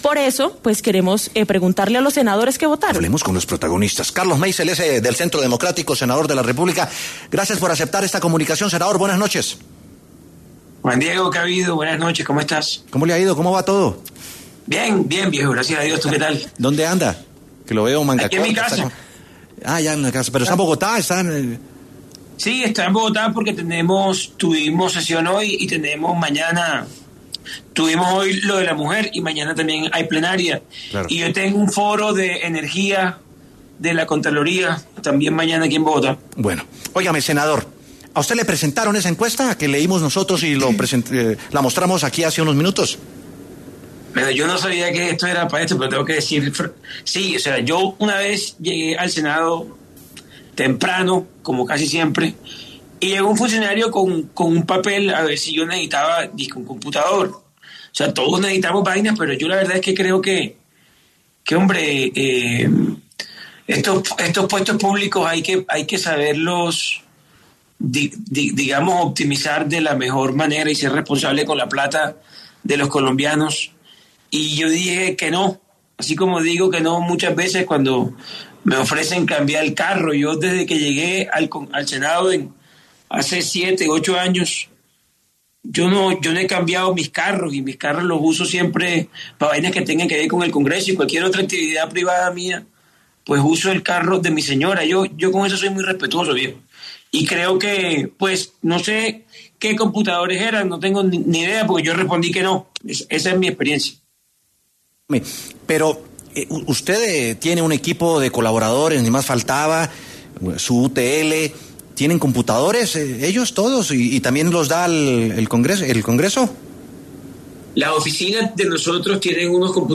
Carlos Meisel, senador por el Centro Democrático, y uno de los que votó por el ‘No’, pasó por W Sin Carreta y aseguró que efectivamente les presentaron una encuesta.